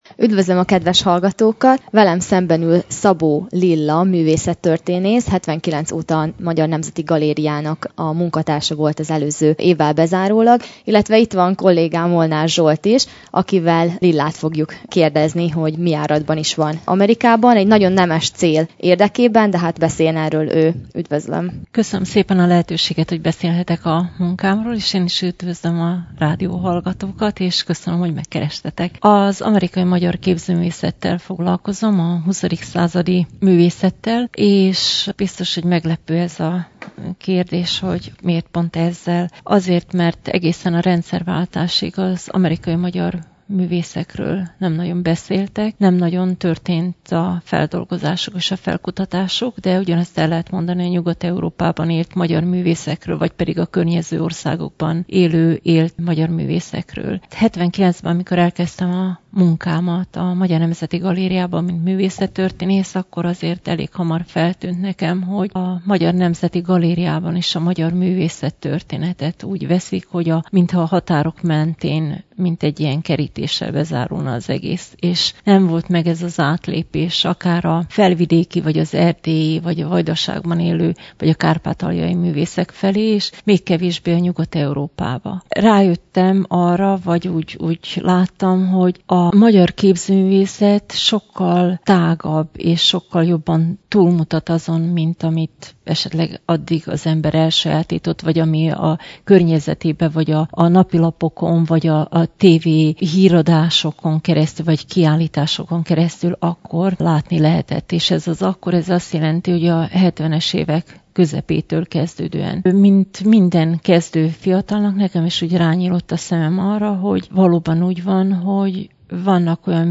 Vele beszélgettünk.